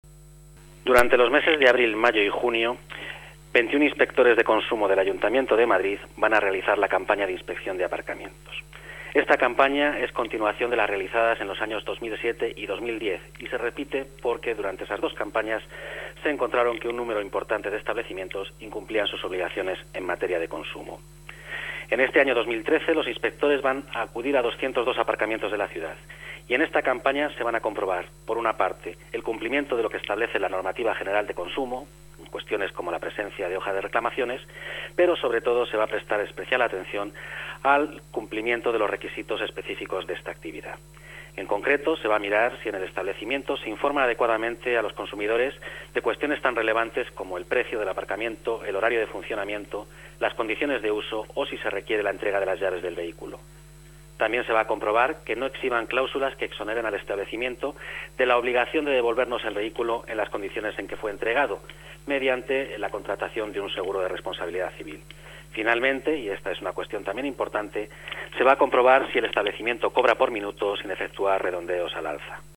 Nueva ventana:Declaraciones de Ángel Sánchez, director general de Consumo: control aparcamientos